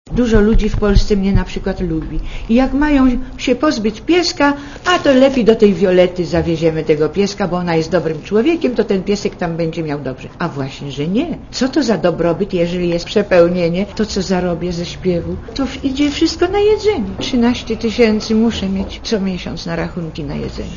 * Dla Radia ZET mówi Violetta Villas*